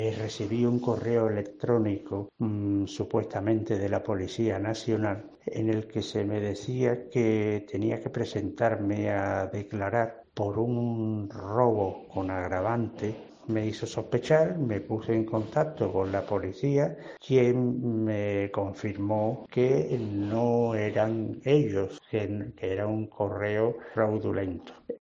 afectado por un intento de fraude